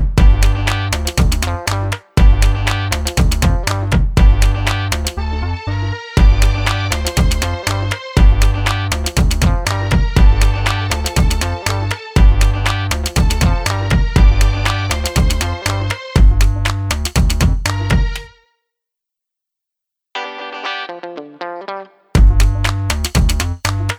no Backing Vocals at all Pop (2010s) 3:22 Buy £1.50